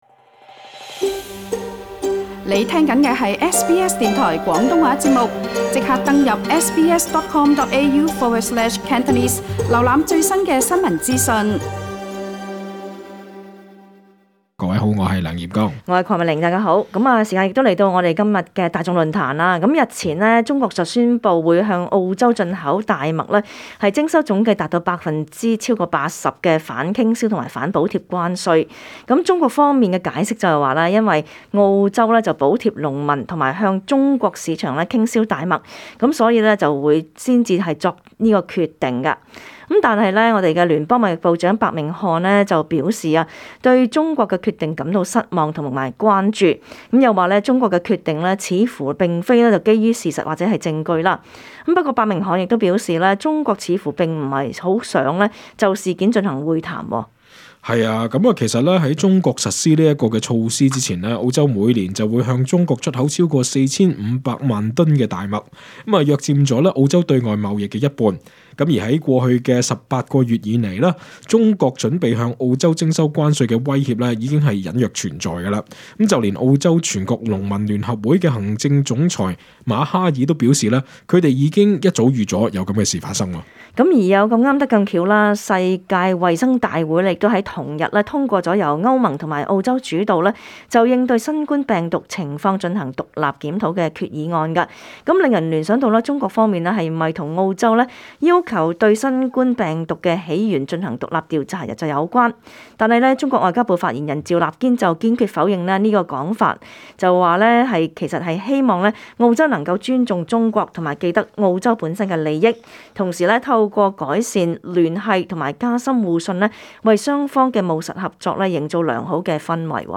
本節目內嘉賓及聽眾意見並不代表本台立場 READ MORE 【新冠疫情】政府限制措施如何影響日常生活 【人傳人】如何可以自保免受威脅？